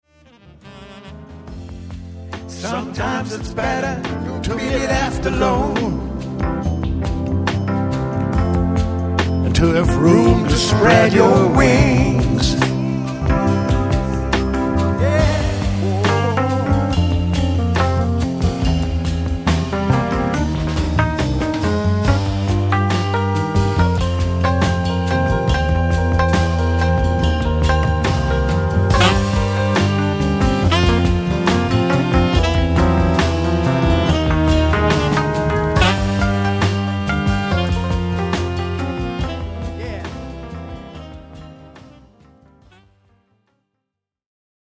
and recorded at home